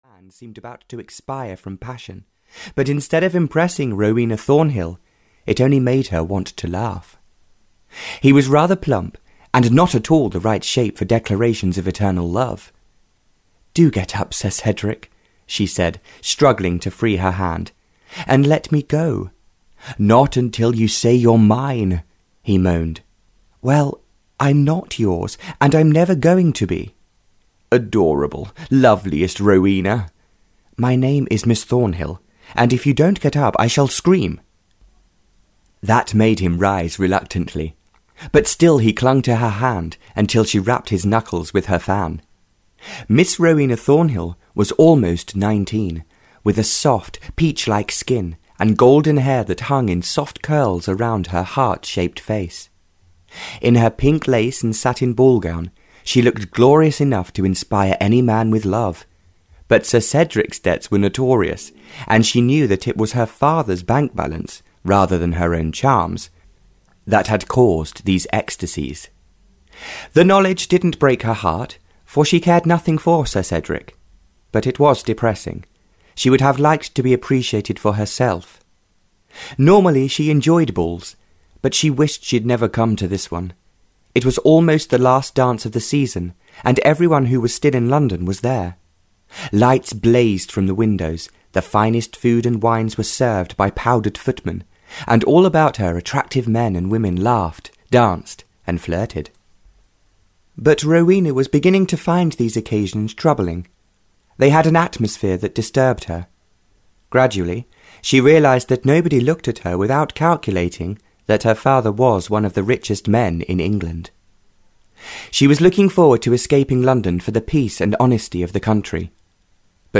The Ship Of Love (Barbara Cartland’s Pink Collection 7) (EN) audiokniha
Ukázka z knihy